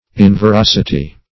Inveracity \In`ve*rac"i*ty\, n. Lack of veracity.